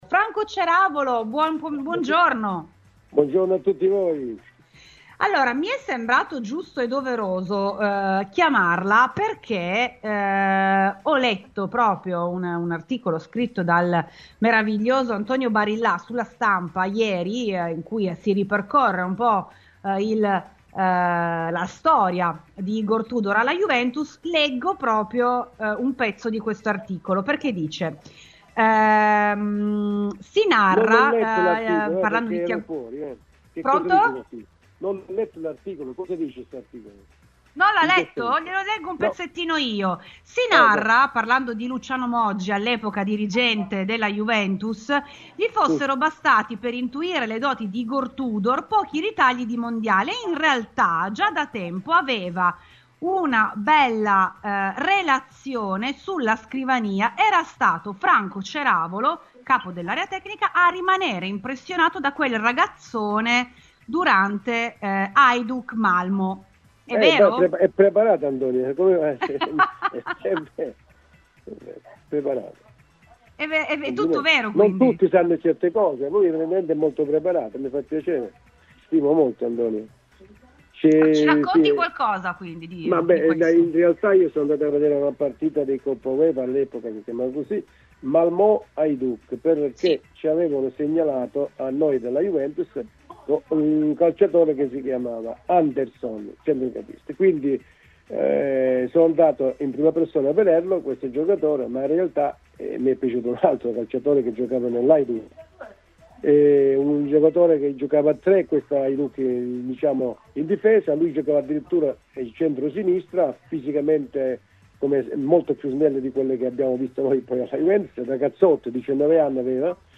Durante la trasmissione RBN Cafè su Radiobianconera questa mattina , abbiamo contattato proprio l'ex dirigente bianconero.